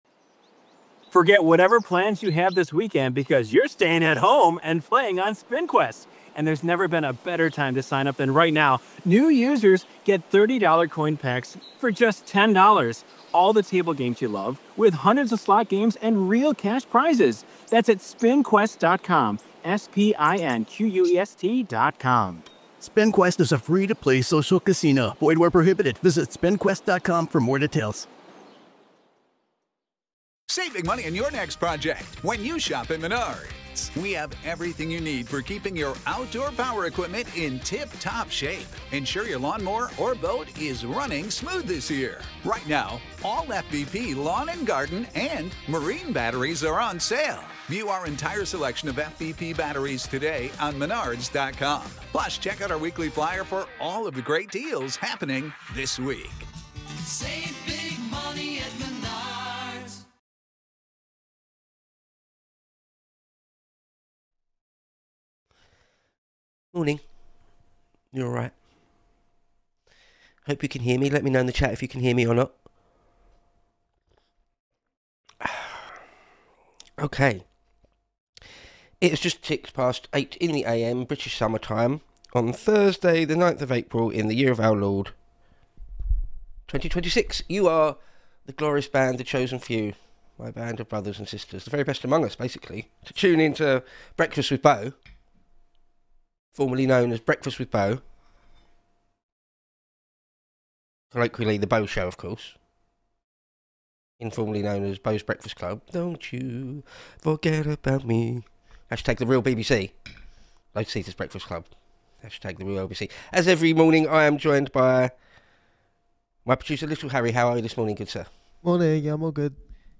Live 8-9am GMT on weekdays.